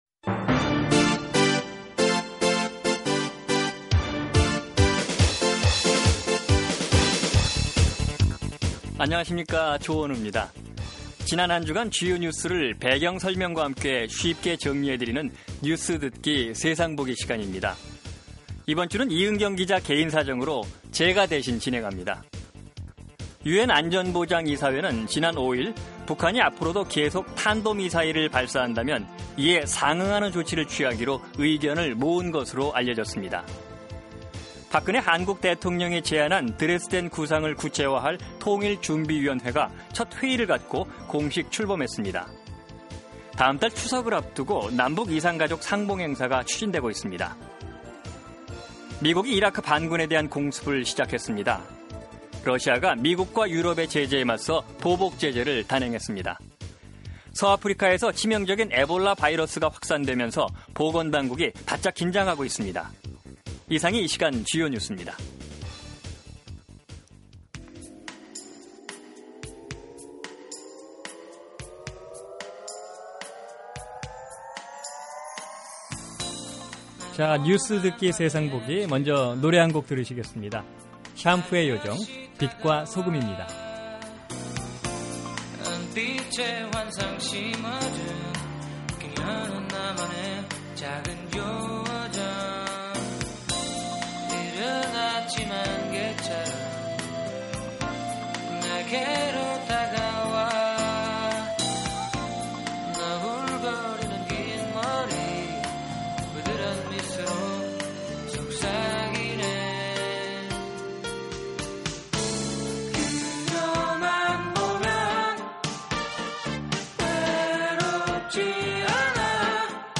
지난 한주간 주요 뉴스를 배경설명과 함께 쉽게 정리해 드리는 뉴스듣기 세상보기 입니다.